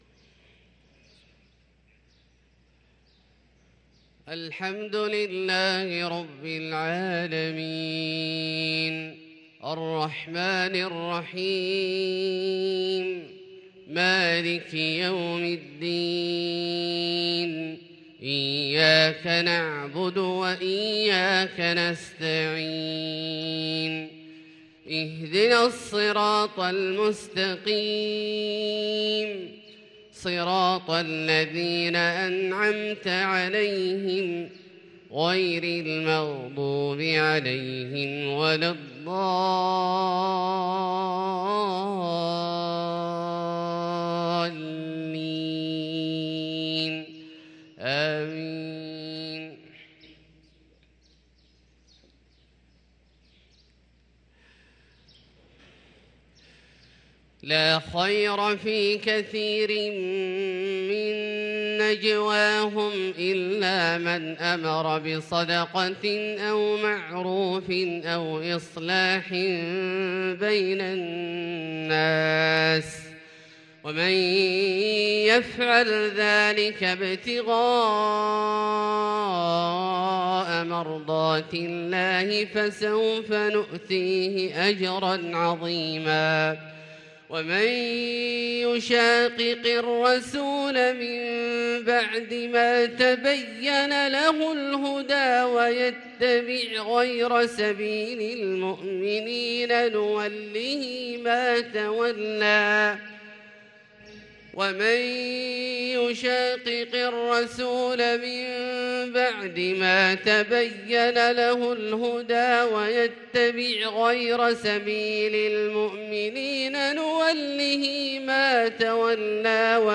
صلاة الفجر للقارئ عبدالله الجهني 17 صفر 1444 هـ
تِلَاوَات الْحَرَمَيْن .